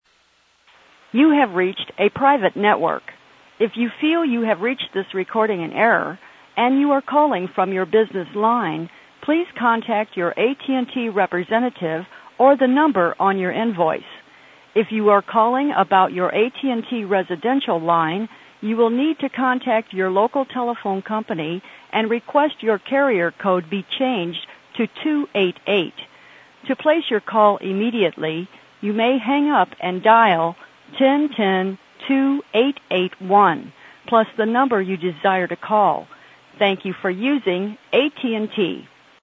AT&T General Information & Error Telephone Sounds & Recordings